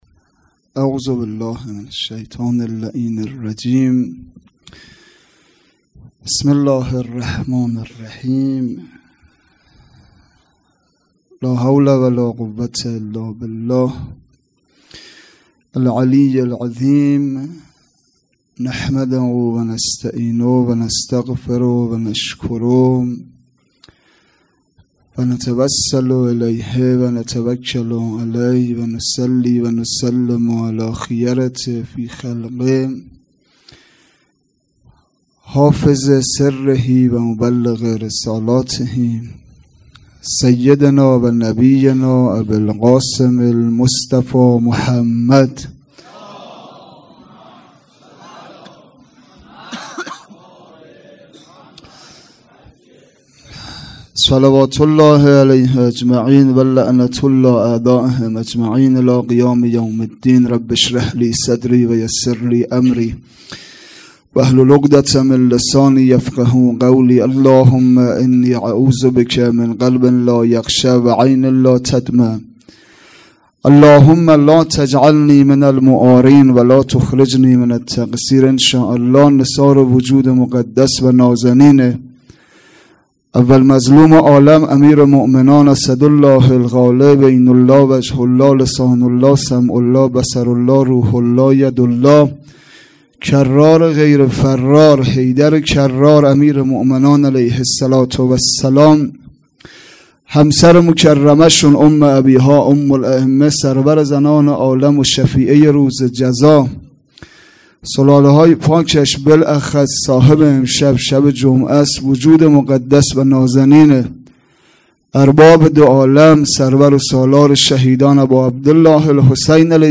مراسم شهادت حضرت زهرا سلام الله علیها فاطمیه دوم ۱۴۰۳